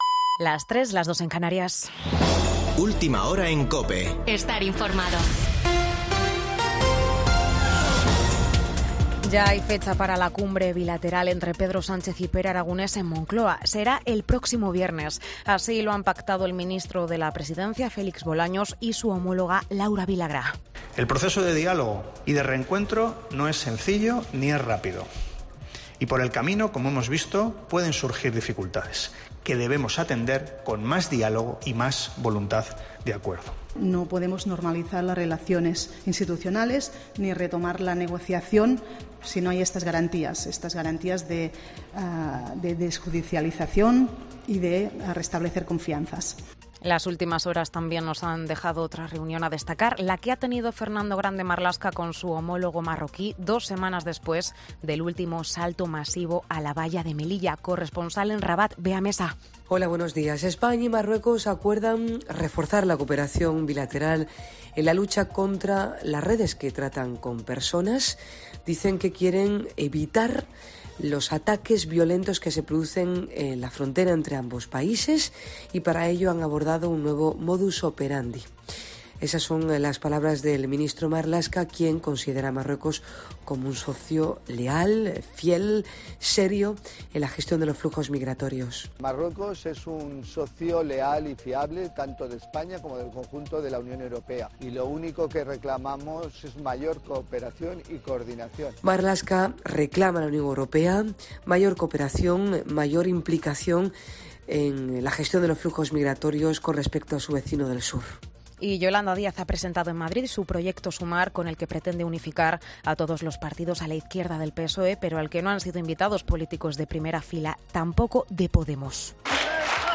Boletín de noticias de COPE del 9 de julio de 2022 a las 03.00 horas